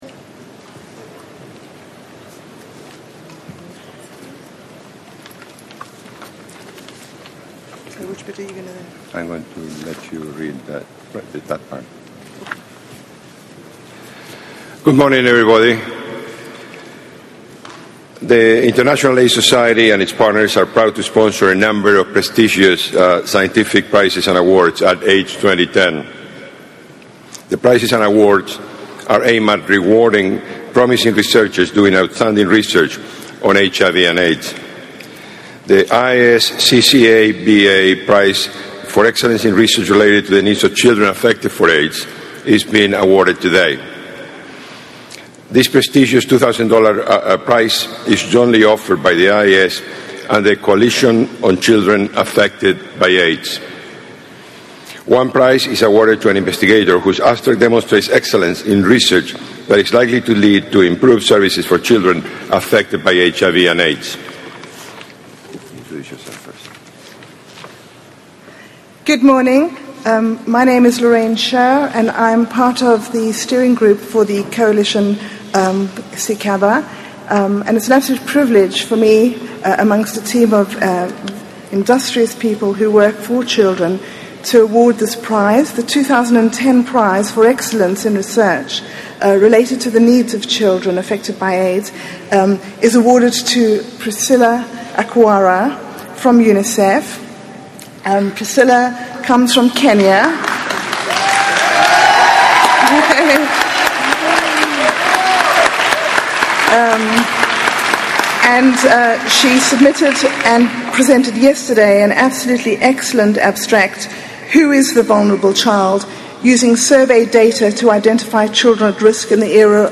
Jonathan Mann Memorial Lecture - Vortrag auf der IAC 2010 Vienna